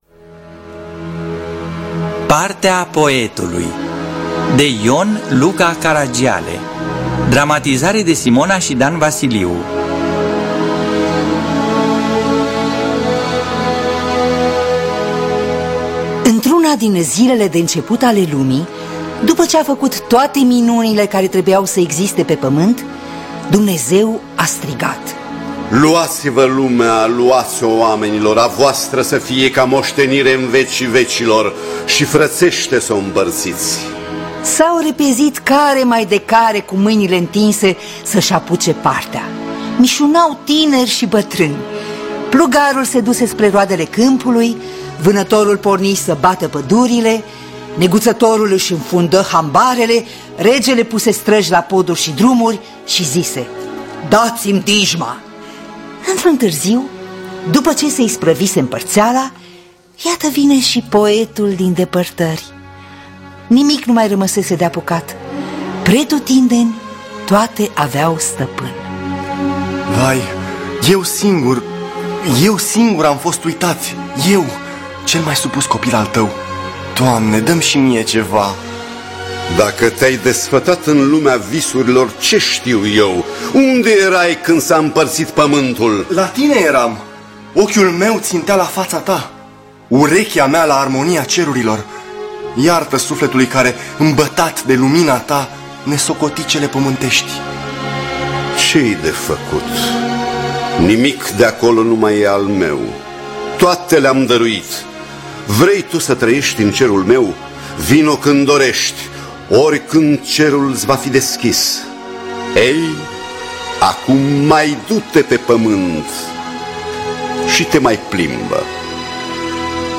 Partea poetului de Ion Luca Caragiale – Teatru Radiofonic Online